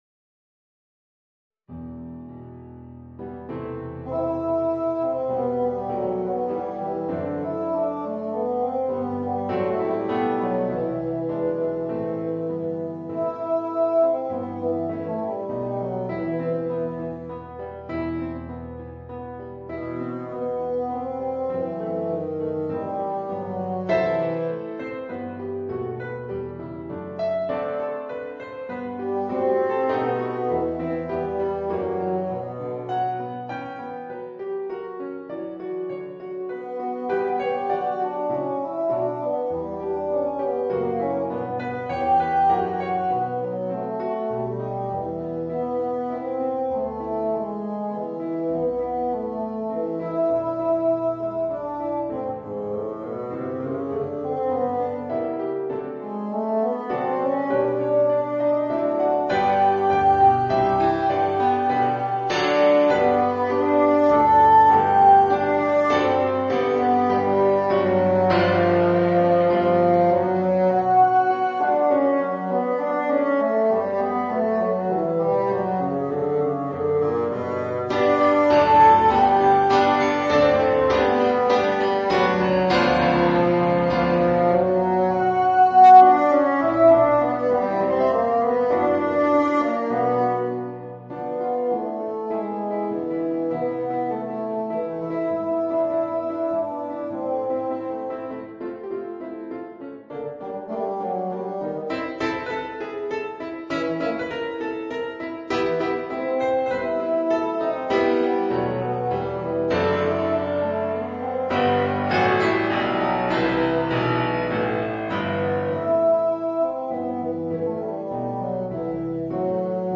Voicing: Bassoon and Piano